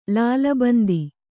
♪ lāla bandi